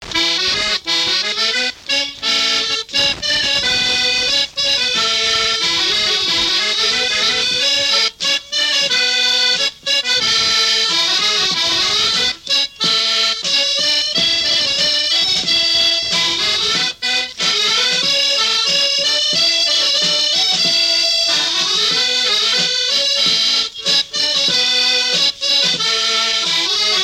danse : scottich trois pas
Genre brève
Pièce musicale inédite